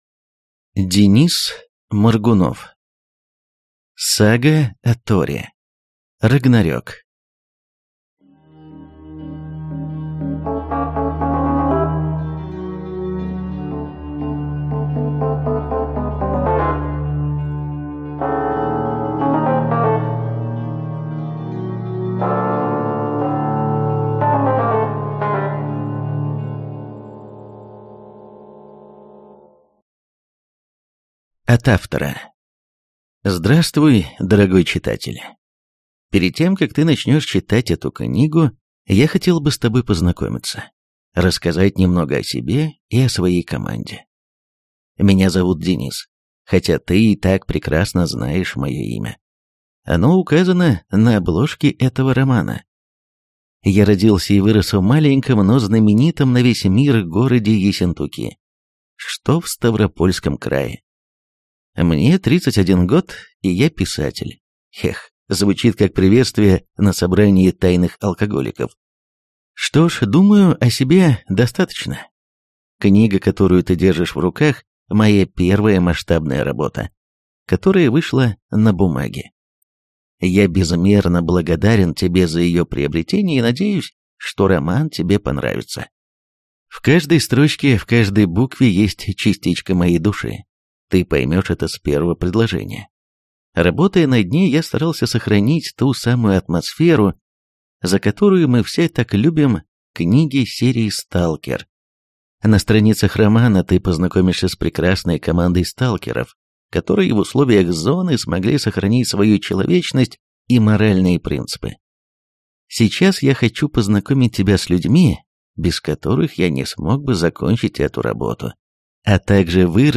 Аудиокнига Сага о Торе. Рагнарёк | Библиотека аудиокниг